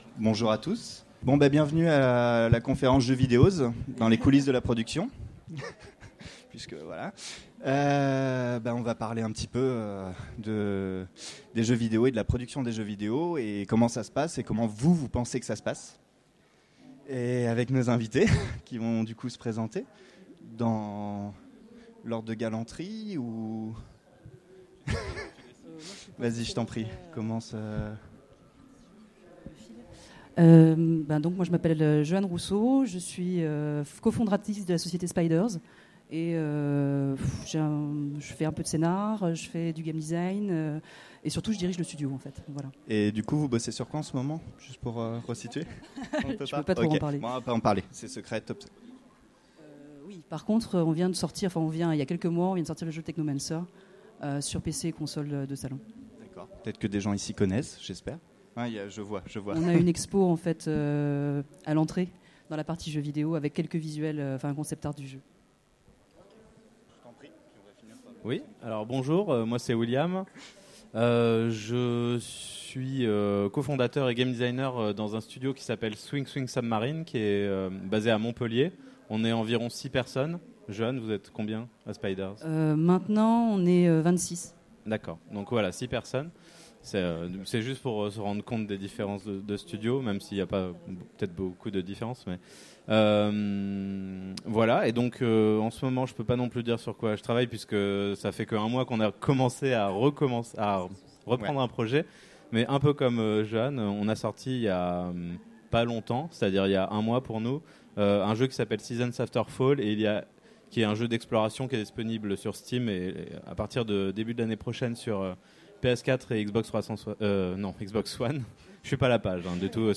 Utopiales 2016 : Conférence Jeux vidéo, dans les coulisses de la production
Conférence